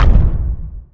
footstep4.wav